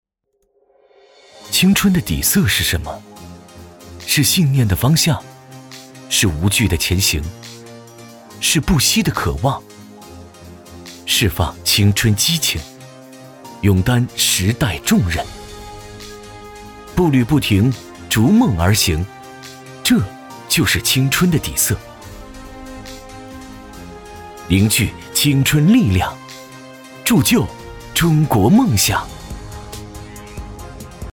国语配音